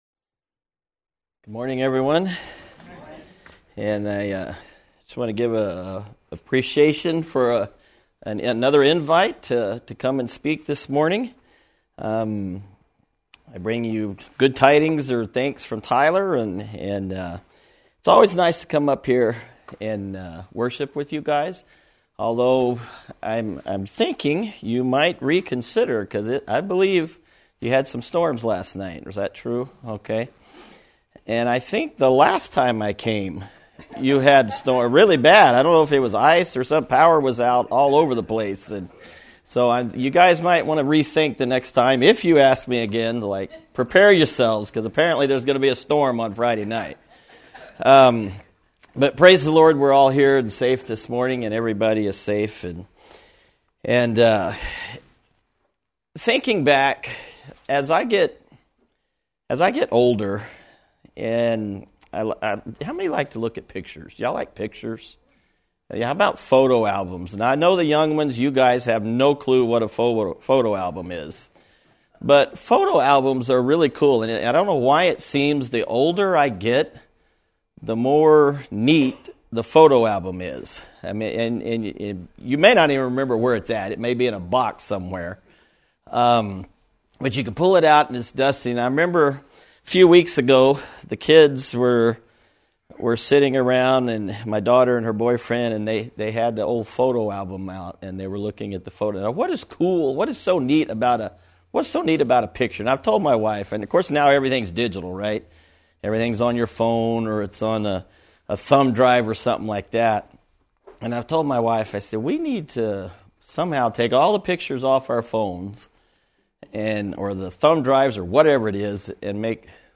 Recorded Church Services